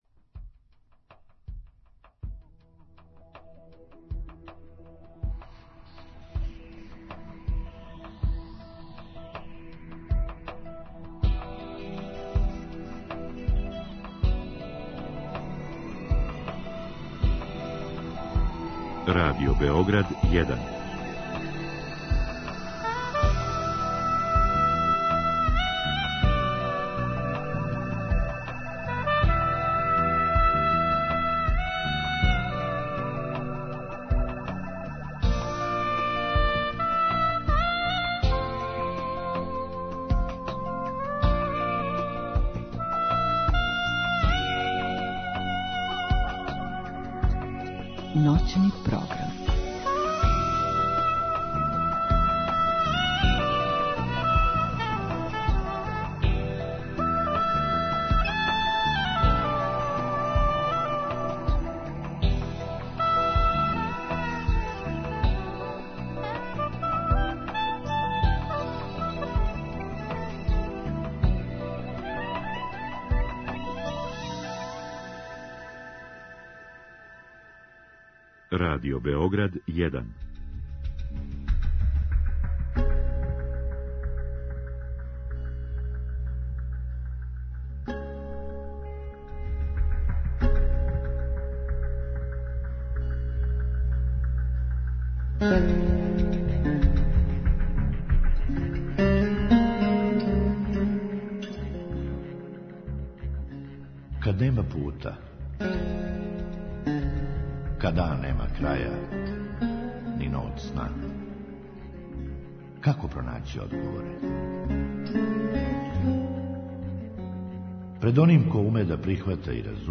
У другом сату слушаоци у директном програму могу поставити питање нашој гошћи.